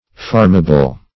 Farmable \Farm"a*ble\, a. Capable of being farmed.